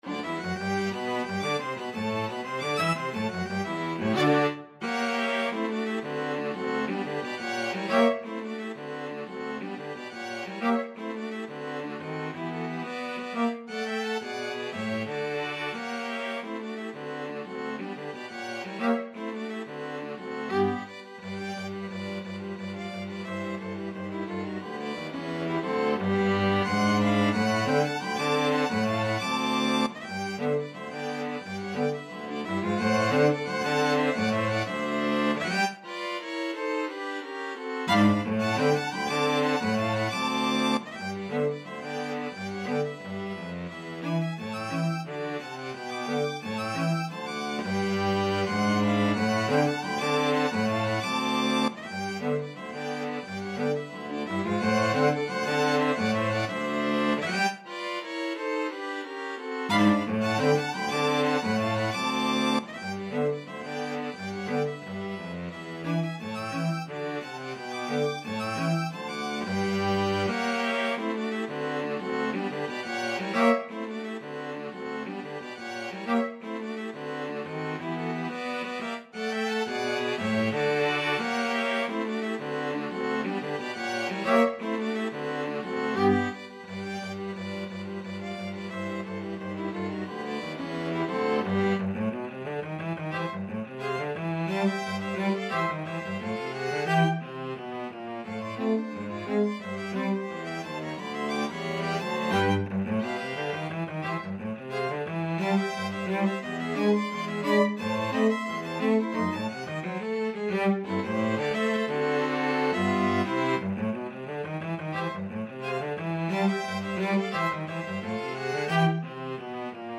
Free Sheet music for String Quartet
Violin 1Violin 2ViolaCello
G major (Sounding Pitch) (View more G major Music for String Quartet )
Slow March Tempo =88
2/4 (View more 2/4 Music)
String Quartet  (View more Advanced String Quartet Music)
Classical (View more Classical String Quartet Music)